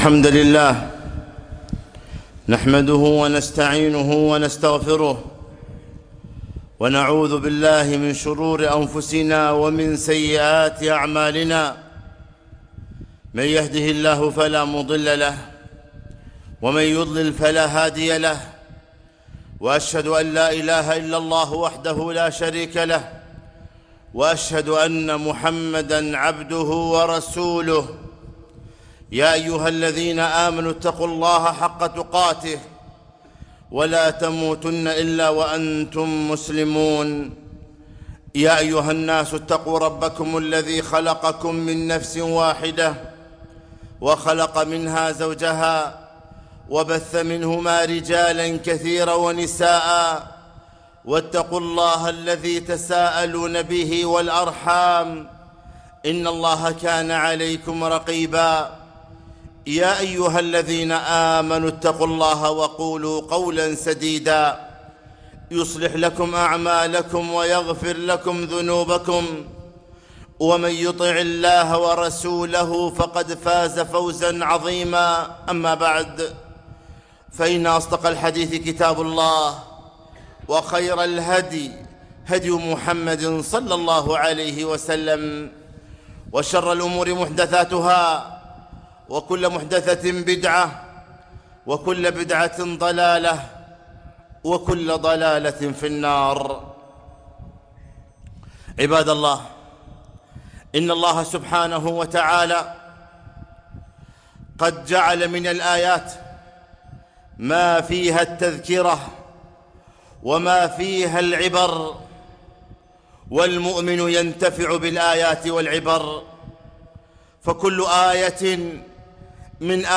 خطبة - وقفات مع حر الصيف